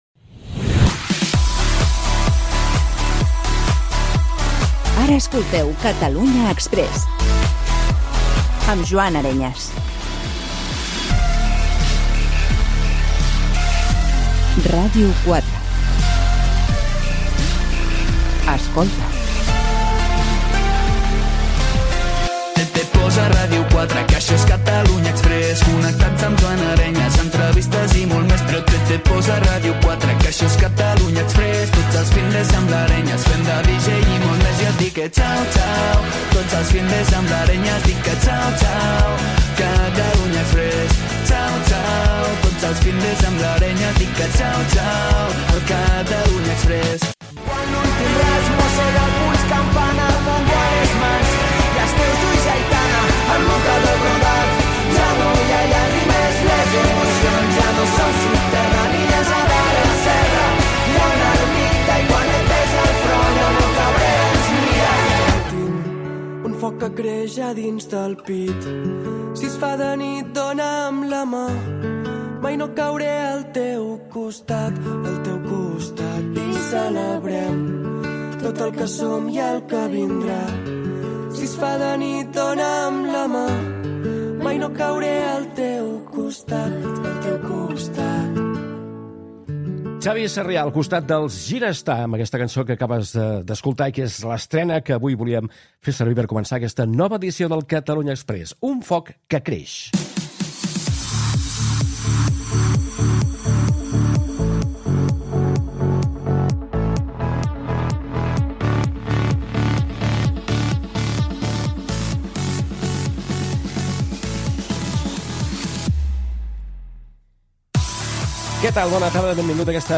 Indicatiu previ del programa, sintonia cantada del programa, tema musical, presentació amb el sumari de continguts, indicatiu del programa, "Batalla de pd's"
Musical